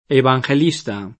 evangelista [evanJel&Sta] (antiq. vangelista) s. m.; pl. ‑sti — sim. il pers. m. Evangelista e i cogn. Evangelista, ‑sti, Vangelista, -sti, D’EvangelistaEvangelista anche cogn. spagnolo [sp.